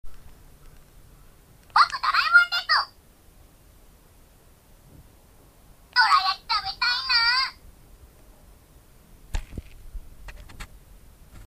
「どらえもん」の左手の赤い部分をつまむと「ぼく ドラえもんです」「どらやきたべたいなぁ～」とおしゃべりします。